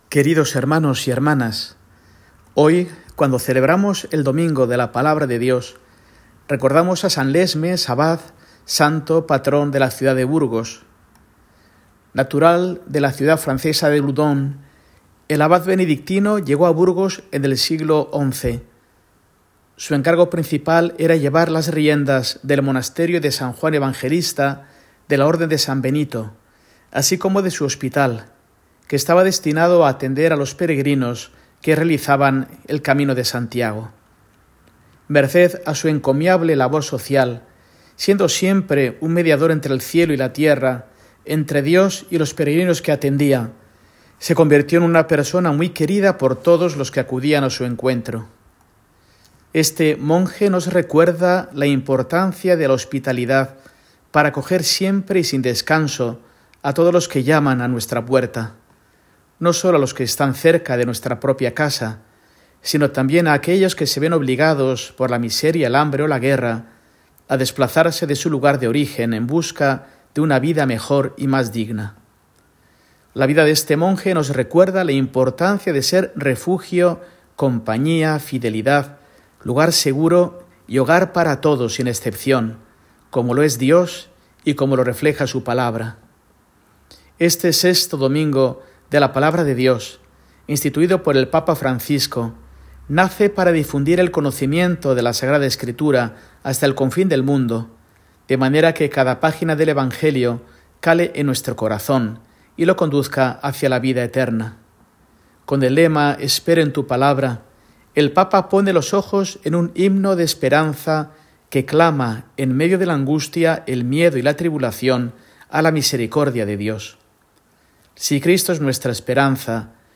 Mensaje semanal de Mons. Mario Iceta Gavicagogeascoa, arzobispo de Burgos, para el domingo, 26 de enero de 2025, Domingo de la Palabra de Dios